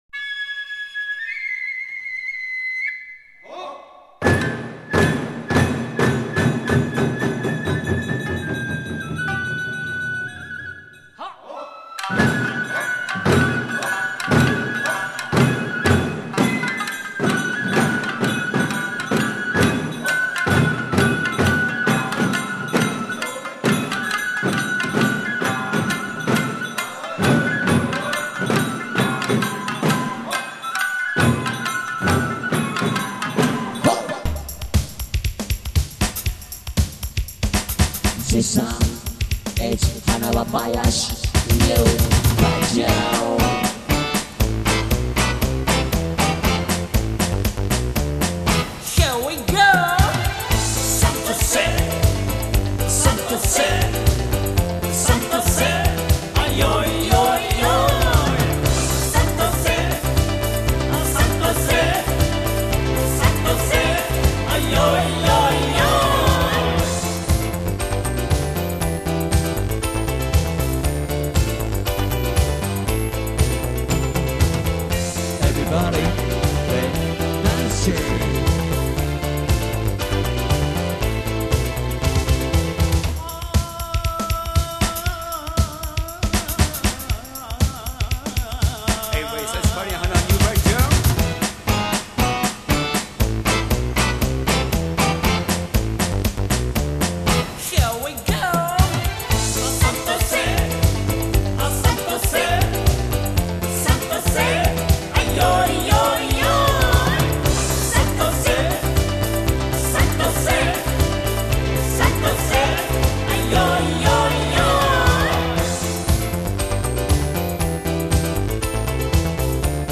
(現代調)